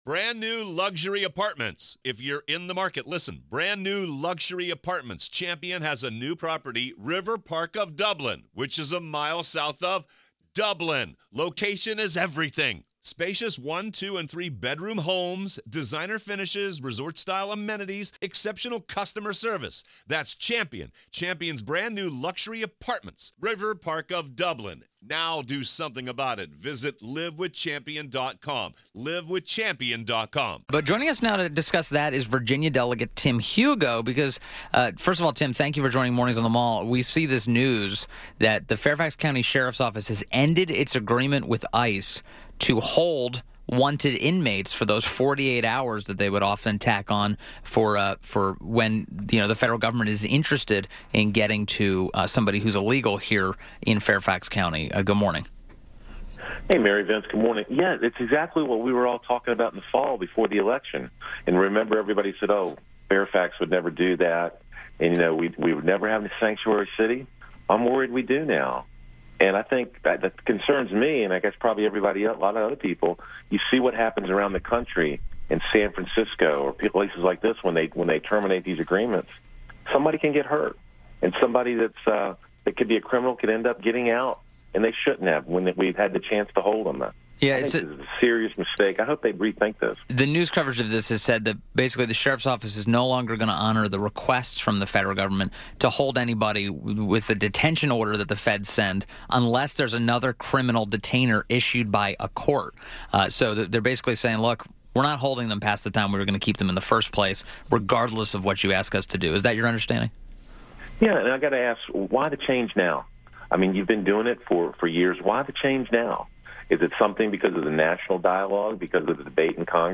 WMAL Interview - VA DEL. TIM HUGO - 01.25.18
INTERVIEW - VA DEL TIM HUGO -- representing the 40th district, which includes the municipalities of Catharpin, Clifton, and Fairfax Station and is the Majority Caucus Chairman for the Virginia House Republican Caucus